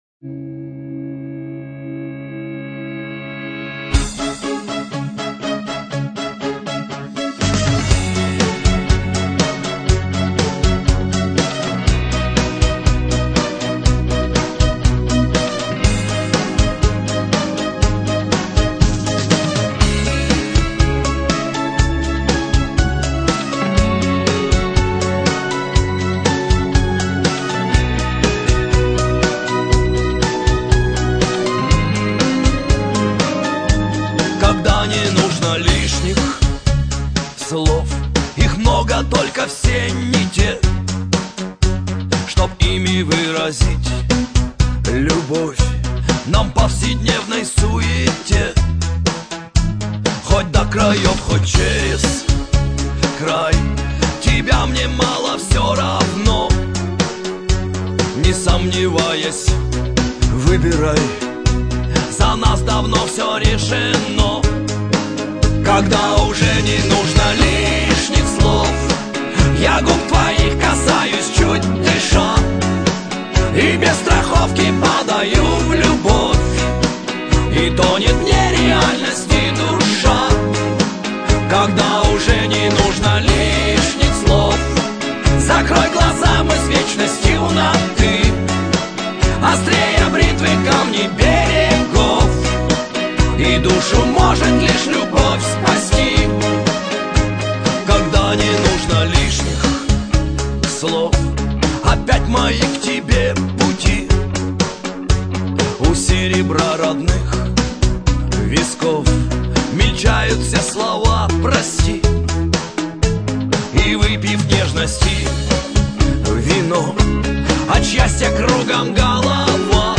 Категория : Шансон (полные)